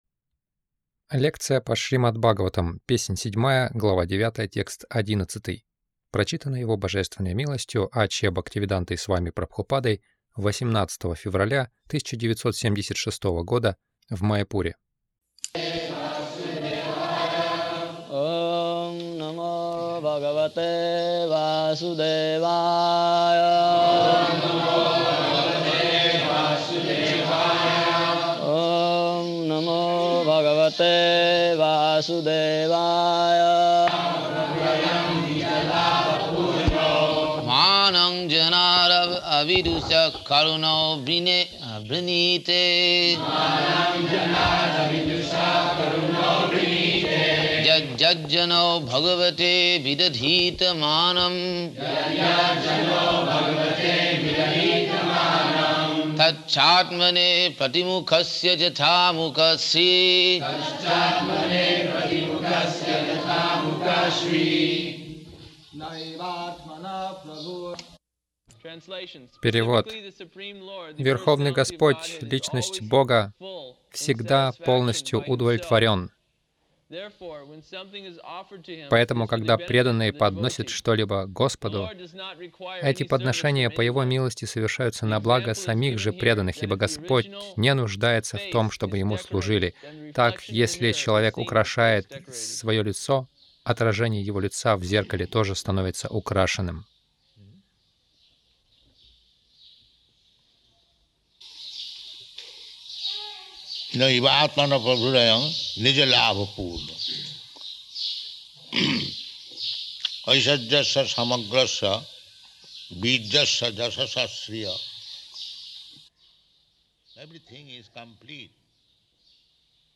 Милость Прабхупады Аудиолекции и книги 18.02.1976 Шримад Бхагаватам | Маяпур ШБ 07.09.11 — Служение Кришне нужно нам, а не Ему Загрузка...